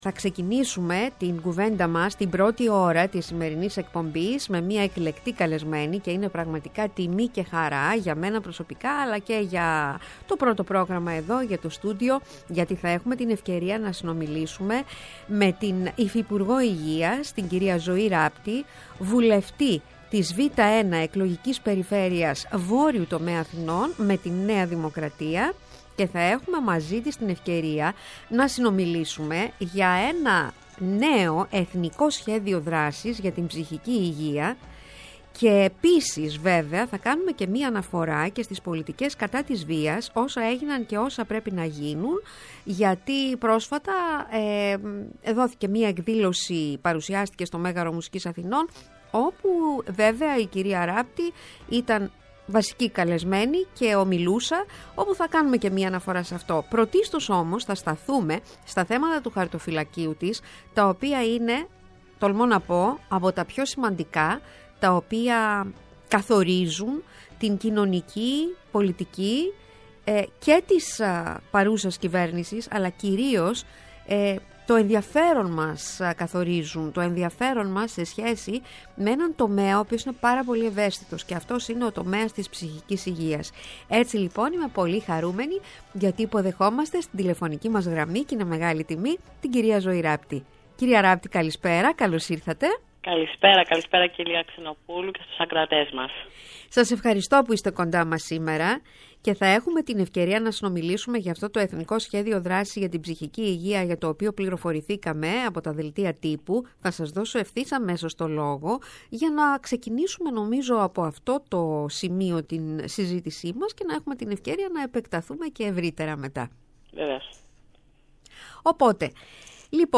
Στις «ΣΥΝΑΝΤΗΣΕΙΣ» στο Πρώτο Πρόγραμμα την Παρασκευή 20-01-23 και ώρα 20:00-22 :00 καλεσμένη τηλεφωνικά: